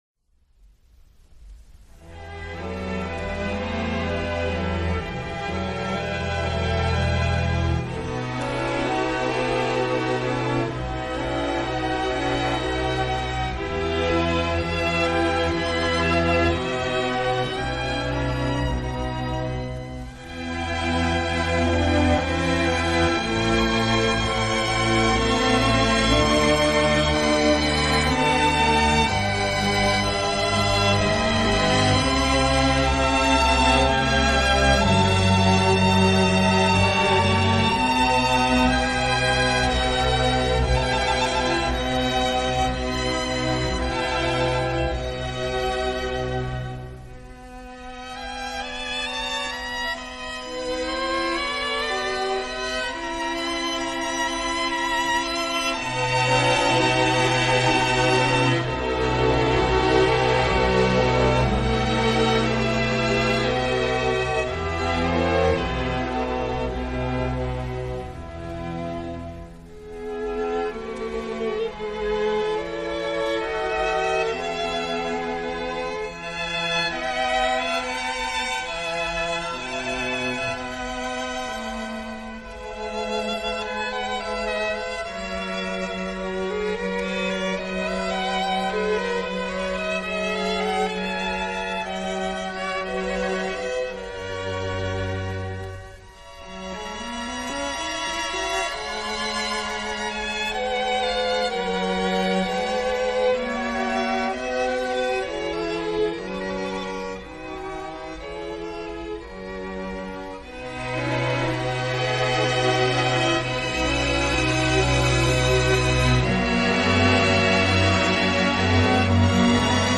موسیقی کلاسیک شاهکار به نام " Wassenaer " از آهنگساز ایتالیایی دوران باروک " جیووانی باتیستا پرگولسی "